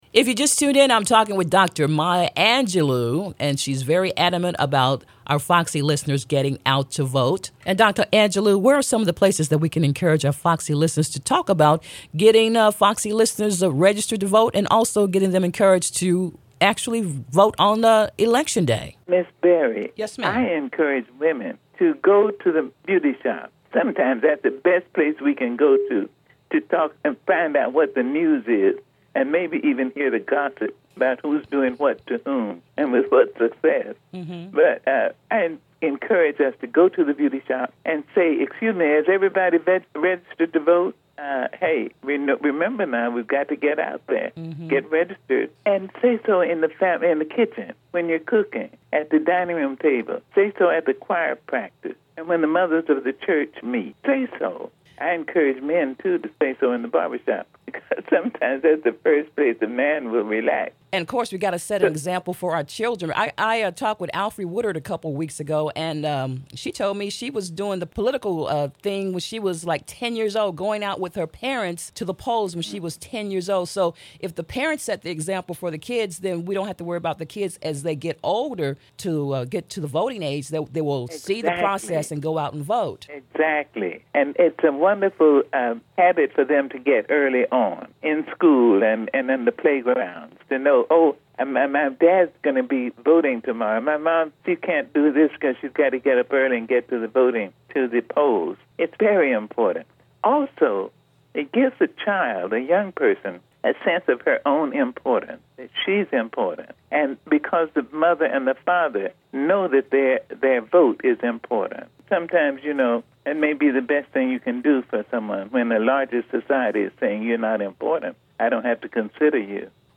In an interview recorded during the Obama re-election campaign in 2012, she took time out of her busy schedule to talk about the importance of voting and the importance of teaching our children the political process.